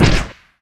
Human_05_Atk.wav